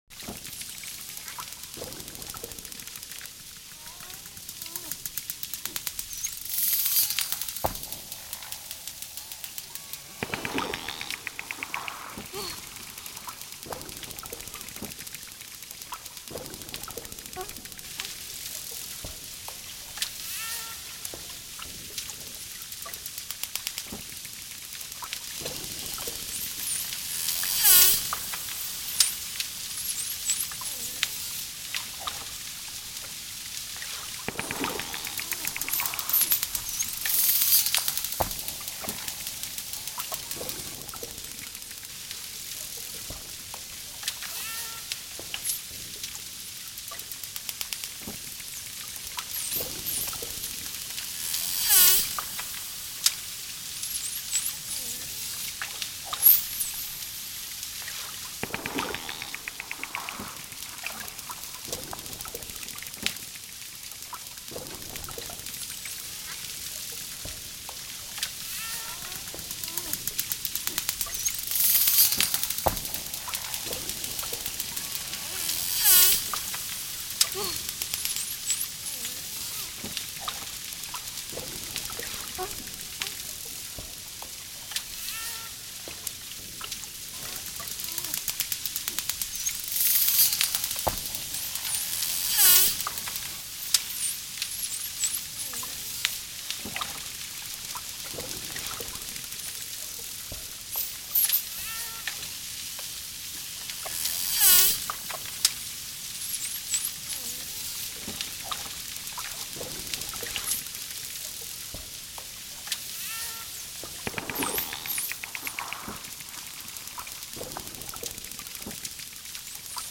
دانلود آهنگ دلفین 3 از افکت صوتی انسان و موجودات زنده
دانلود صدای دلفین 3 از ساعد نیوز با لینک مستقیم و کیفیت بالا
جلوه های صوتی